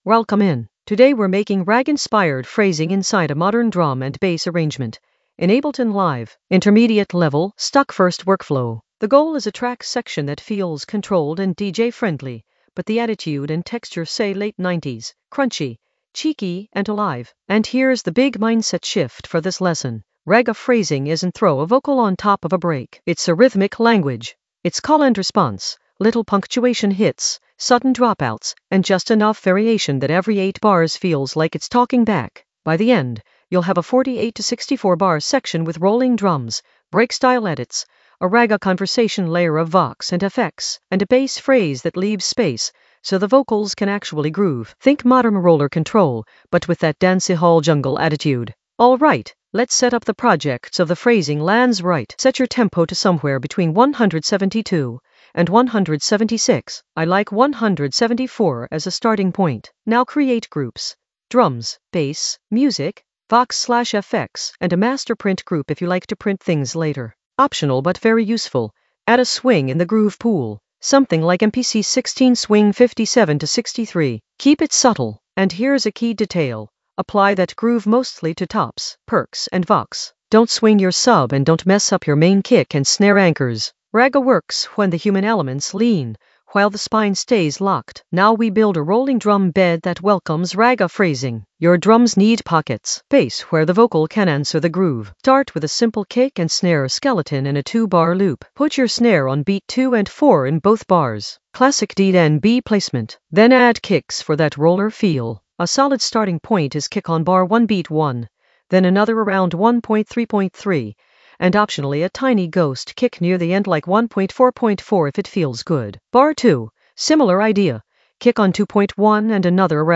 Narrated lesson audio
The voice track includes the tutorial plus extra teacher commentary.
An AI-generated intermediate Ableton lesson focused on Ragga-inspired phrasing for modern control with vintage tone in the Composition area of drum and bass production.